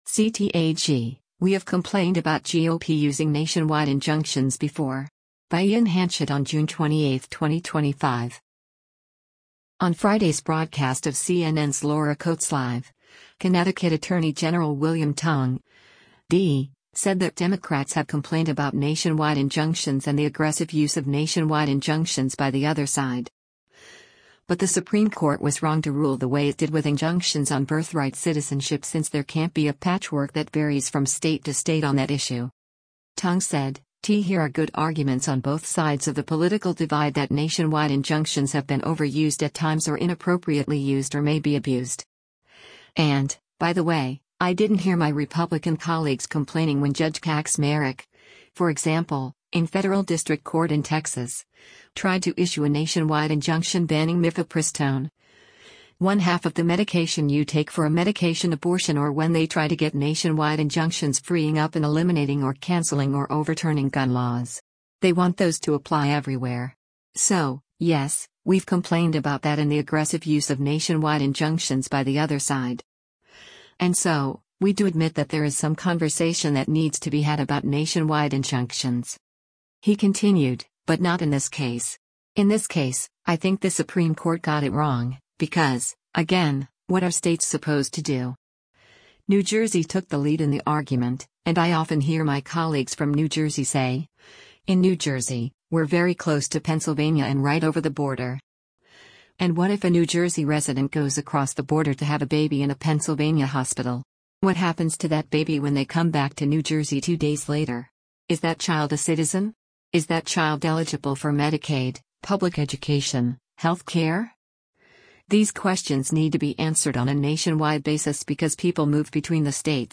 On Friday’s broadcast of CNN’s “Laura Coates Live,” Connecticut Attorney General William Tong (D) said that Democrats have “complained” about nationwide injunctions “and the aggressive use of nationwide injunctions by the other side.” But the Supreme Court was wrong to rule the way it did with injunctions on birthright citizenship since there can’t be a patchwork that varies from state to state on that issue.